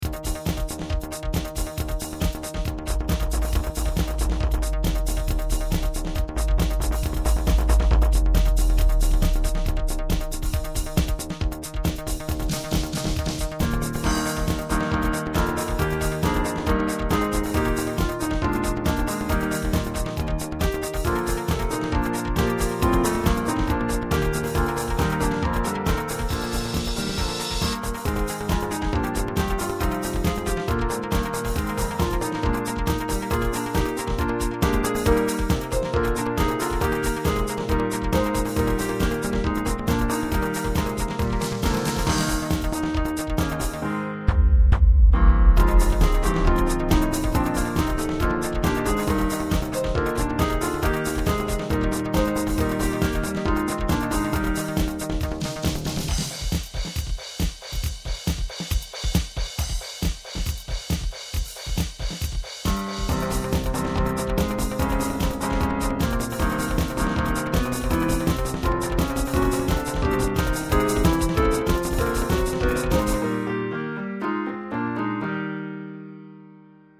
Sydney Boys' High School Song (Techno)